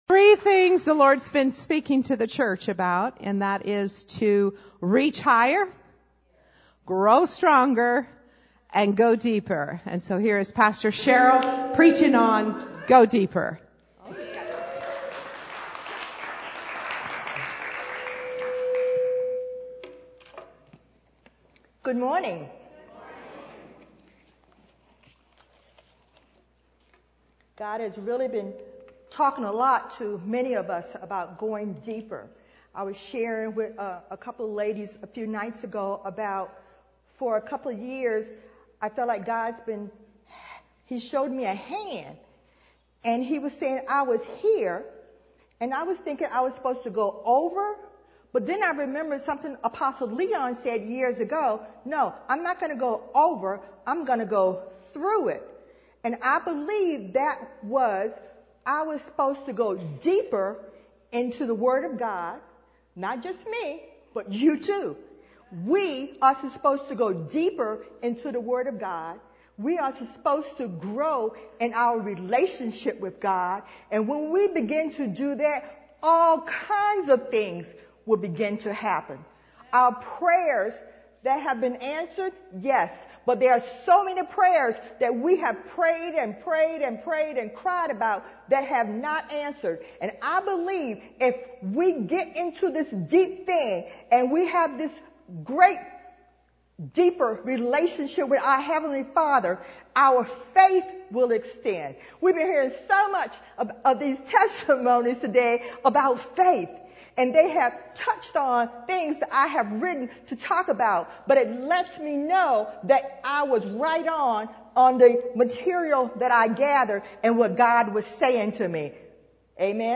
Sermons Archive - River of Life Community Church